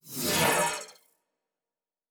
pgs/Assets/Audio/Sci-Fi Sounds/Doors and Portals/Teleport 2_2.wav at master
Teleport 2_2.wav